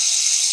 spray.ogg